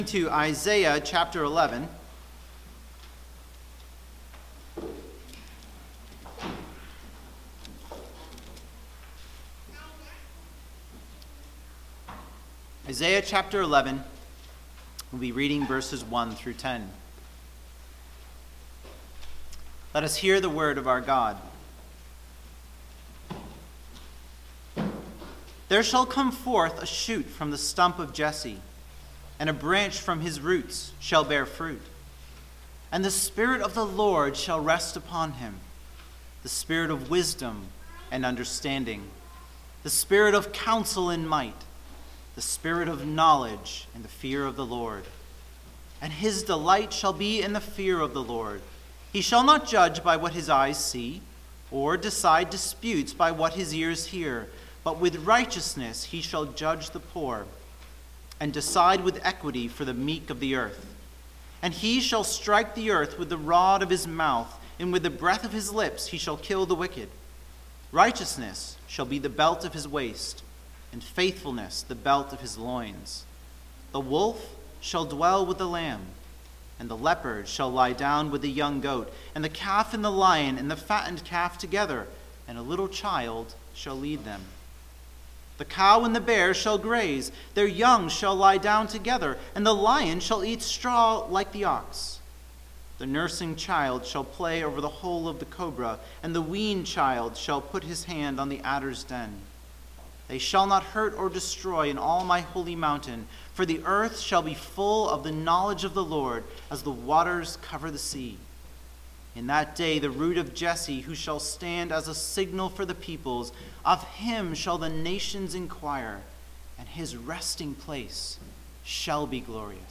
Sermon on Colossians 2: 1-5
Service Type: Sunday Morning